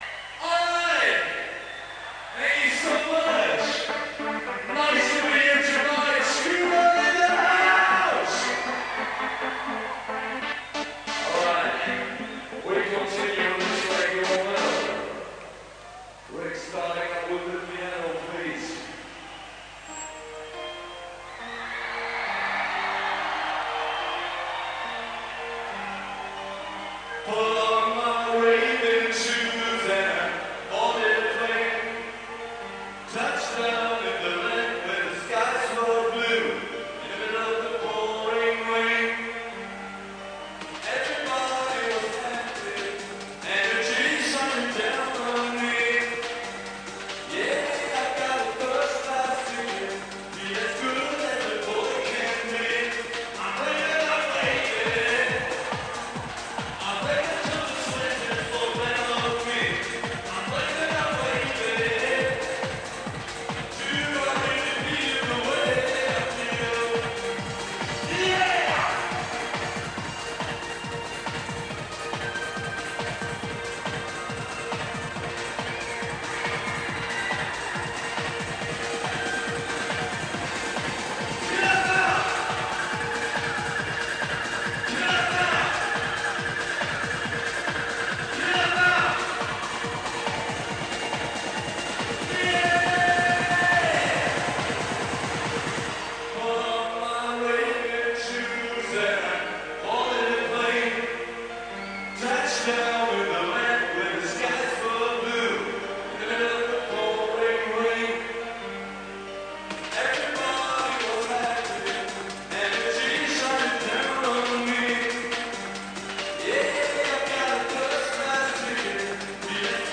koncert w toruniu